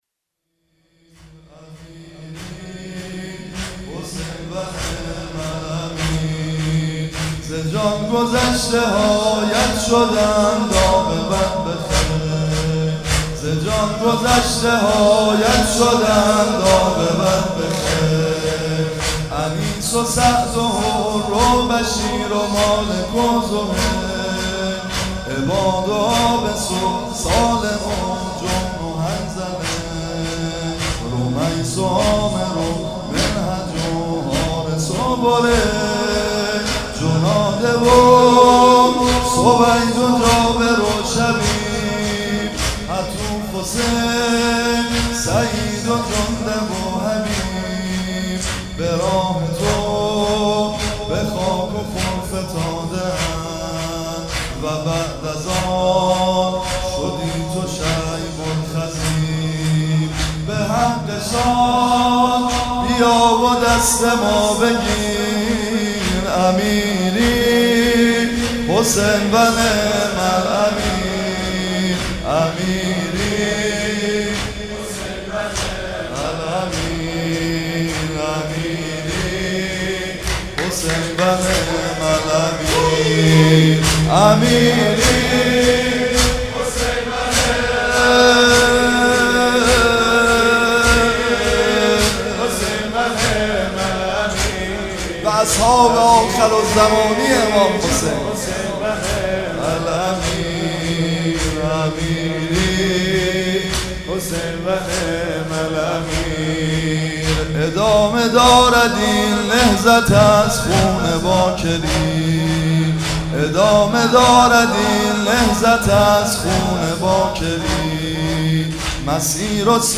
هئیت رزمندگان غرب تهران/رمضان96
مناجات